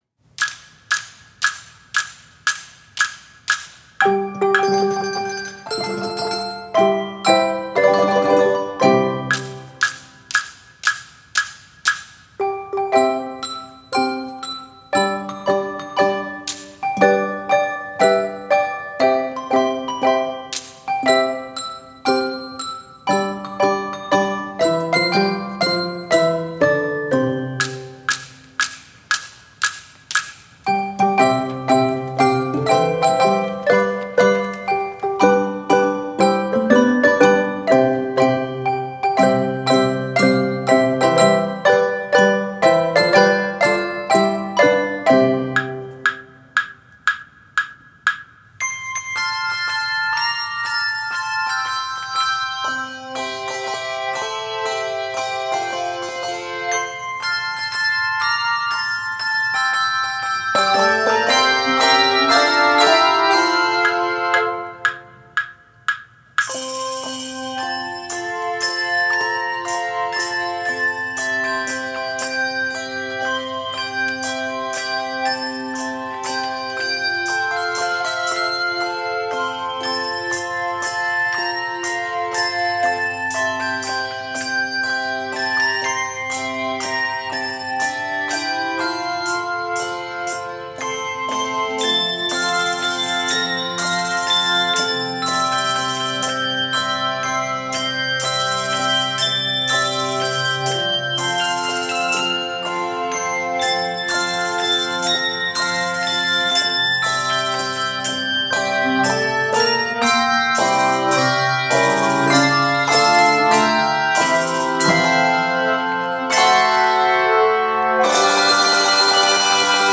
Keys of C Major and F Major.